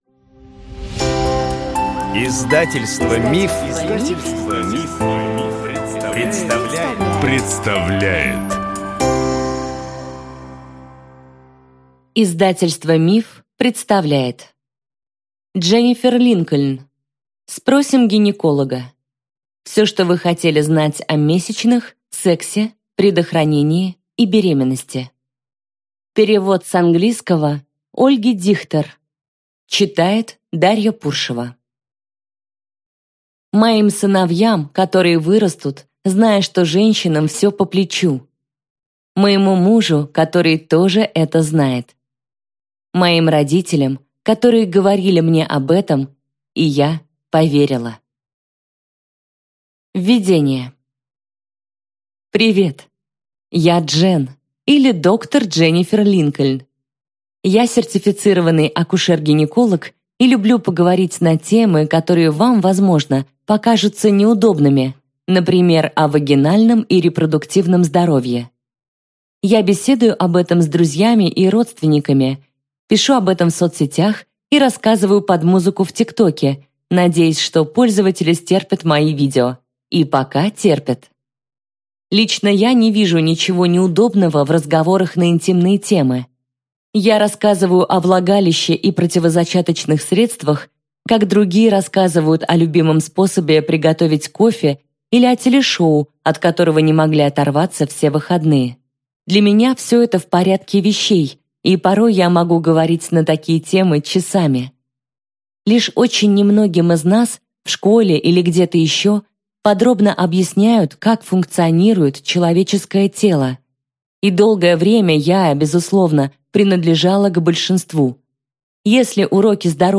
Аудиокнига Спросим гинеколога. Все, что вы хотели знать о месячных, сексе, предохранении и беременности | Библиотека аудиокниг